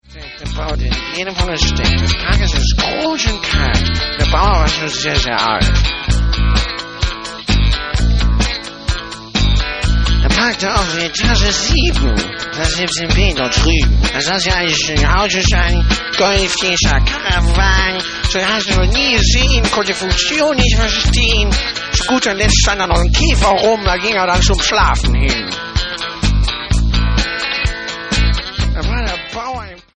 Radikale Sounds der frühen 80er-Jahre.